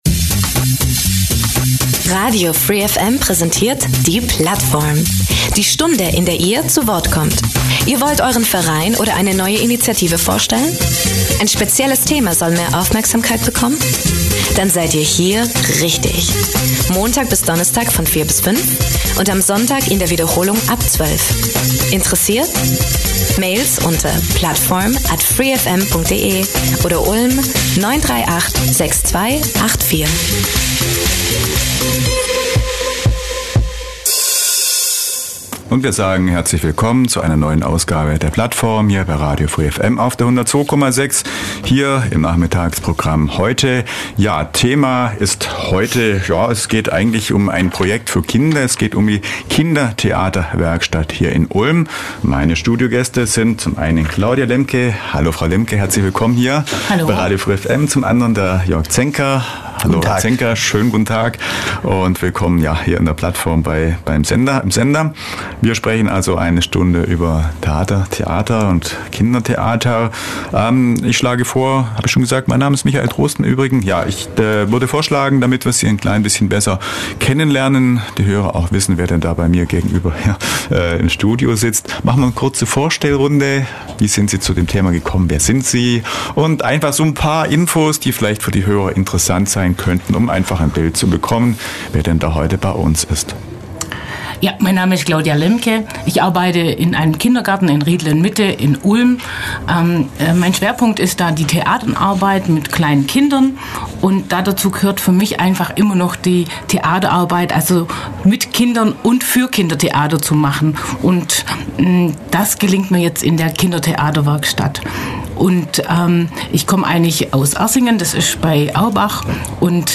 Wir haben die Direktkandidaten zum Gespräch eingeladen.